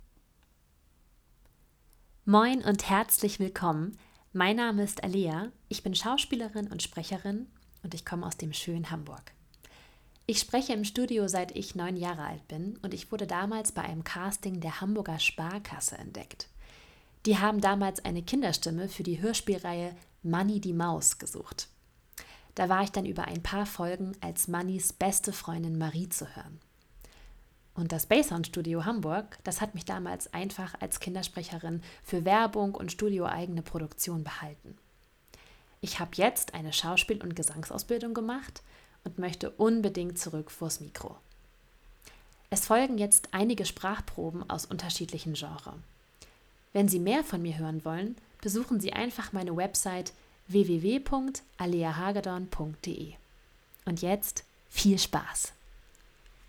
Dialekt hamburgisch
SPRECHER Demoreel
Begrüßung